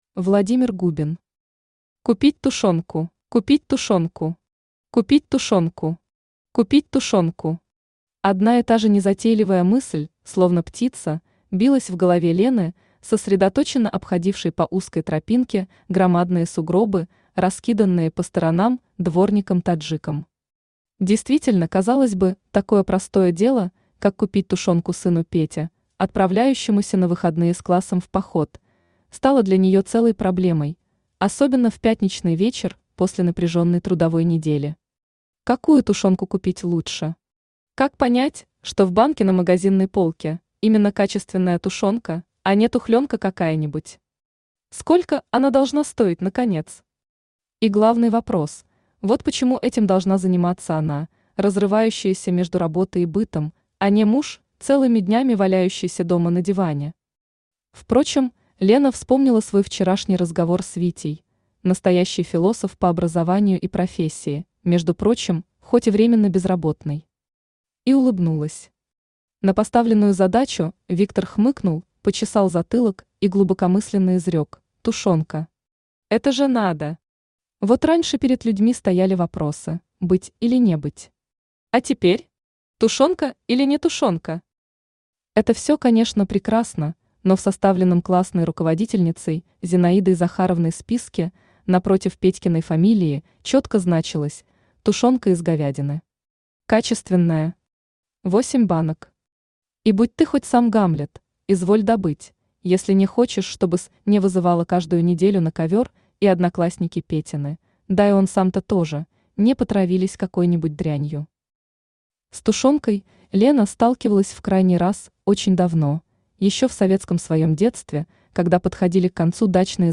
Аудиокнига Купить тушёнку!
Автор Владимир Губин Читает аудиокнигу Авточтец ЛитРес.